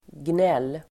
Uttal: [gnel:]